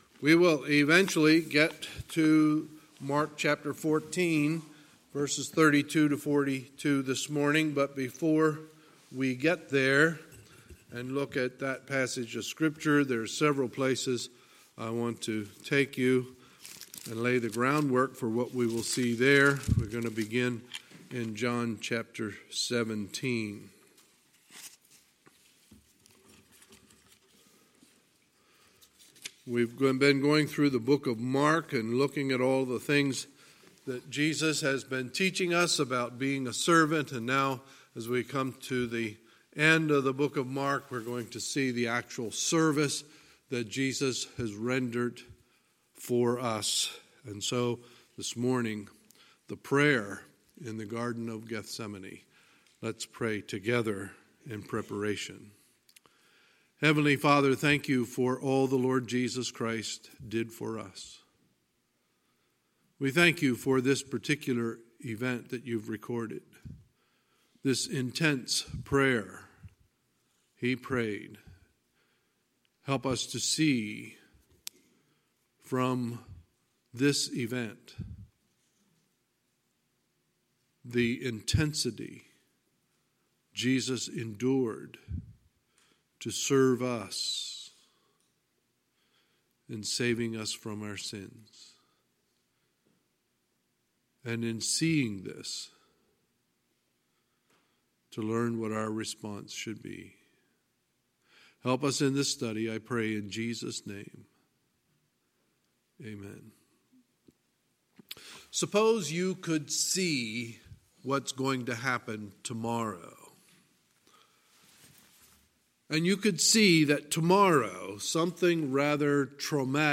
Sunday, October 27, 2019 – Sunday Morning Service